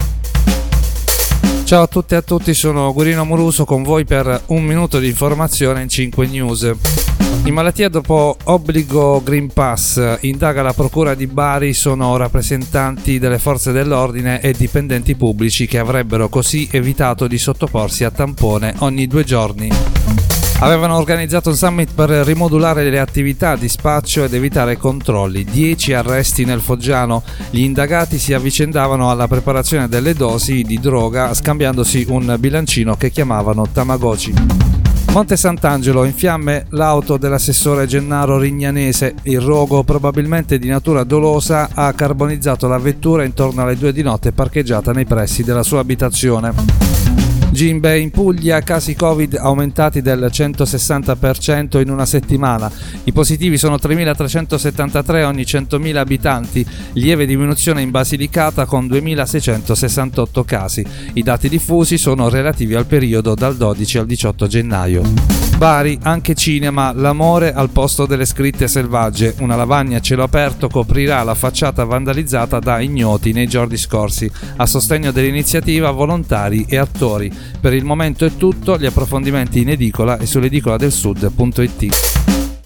Giornale radio alle ore 13.